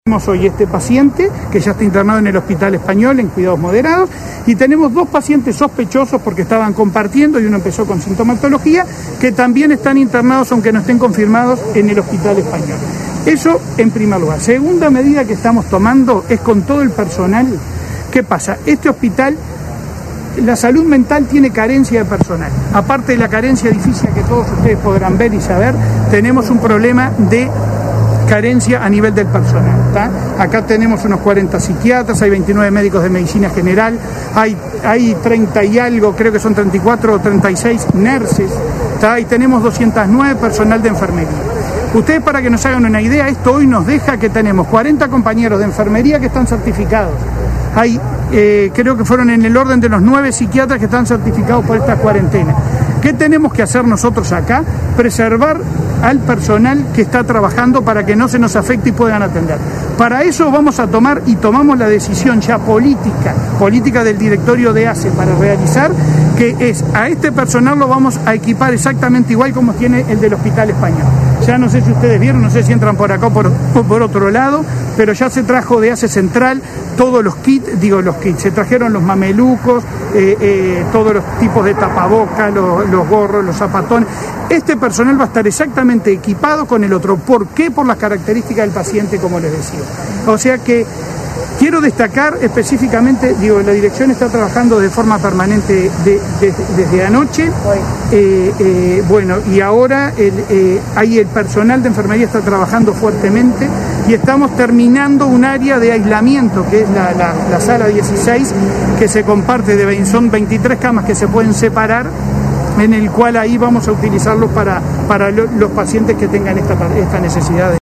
“Vamos a utilizarlo para los pacientes que tengan estas necesidades”, expresó Cipriani en rueda de prensa, en las afueras del Vilardebó este lunes. El jerarca dijo que se debe hacer hincapié en un protocolo de actuación en centros de salud para evitar comportamientos que violen las medidas de aislamiento y prevención.